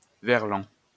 Verlan (pronounced [vɛʁlɑ̃]